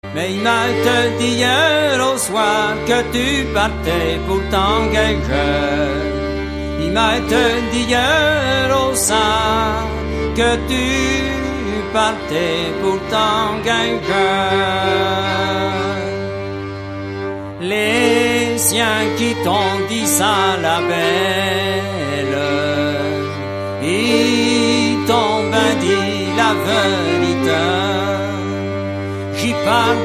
Musique : Traditionnel
Interprètes : Les Routiniers
Échelles : 5 degrés Mode de La sans 4e ni 6e 5-7123-5